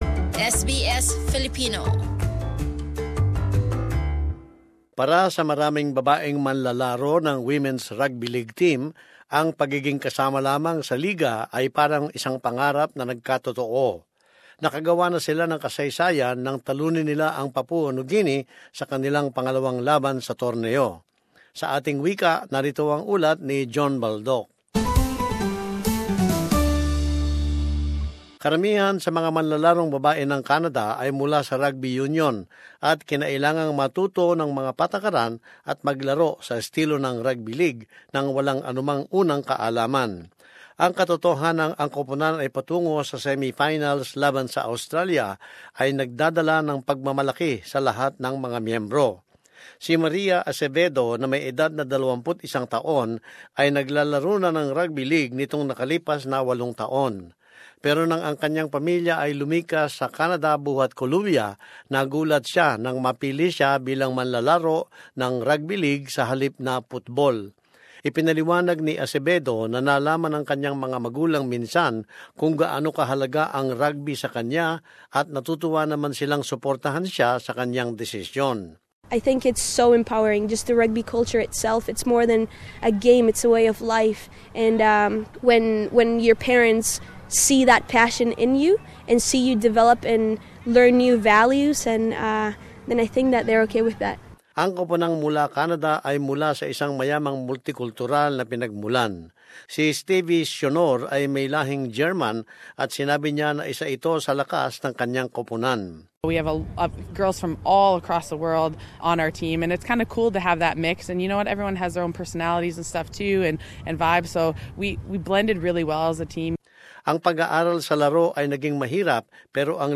And as this report, they've already made history by beating Papua New Guinea in their second match at the tournament.